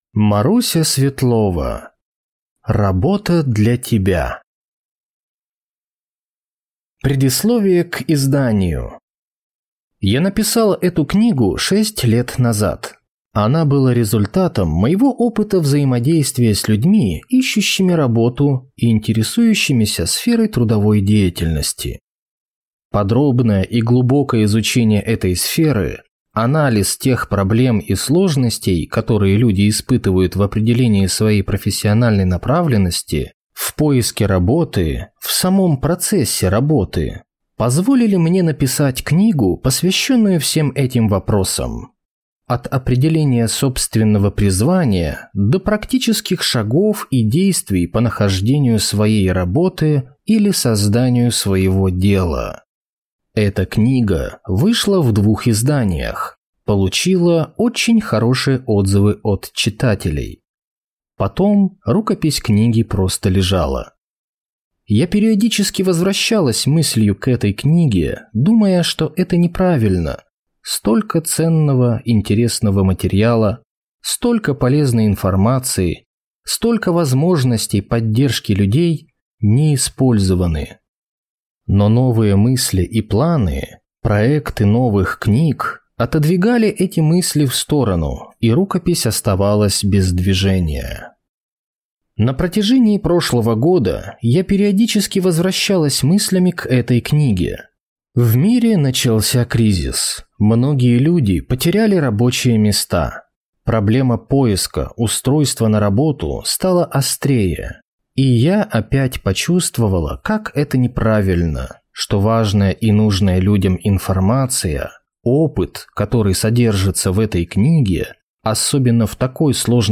Аудиокнига Работа для тебя | Библиотека аудиокниг